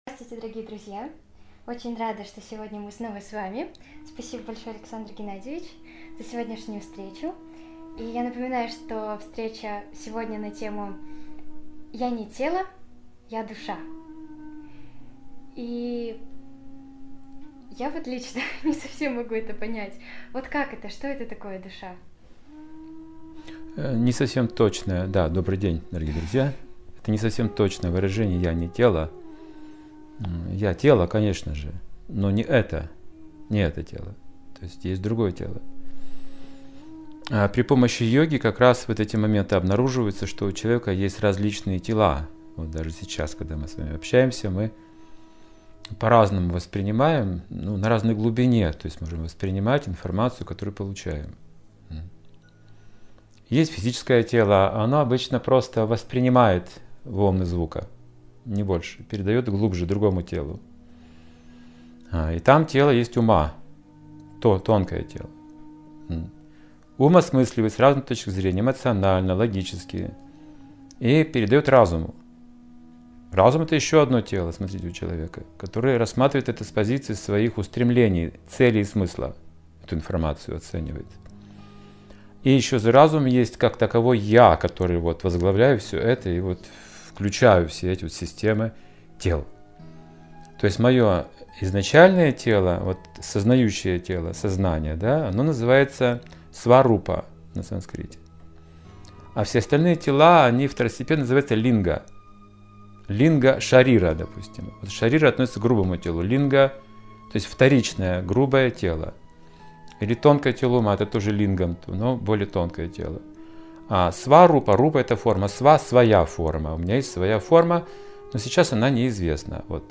Алматы